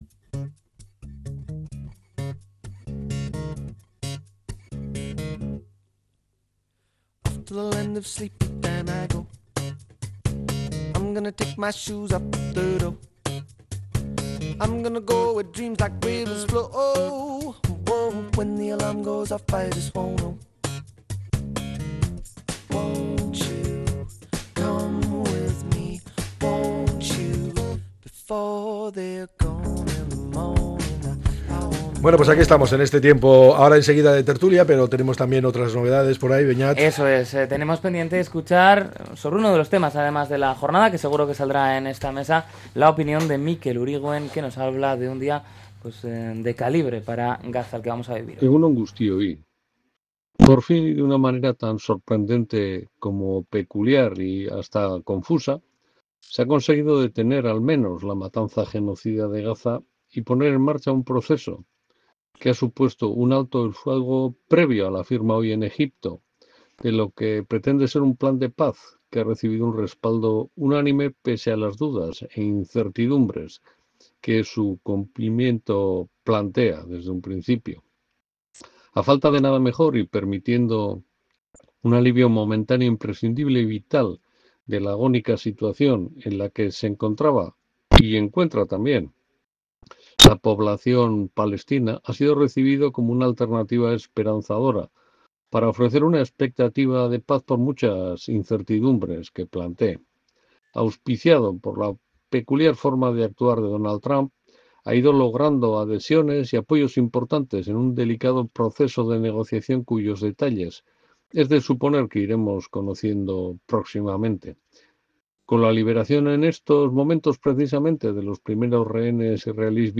La tertulia 13-10-25.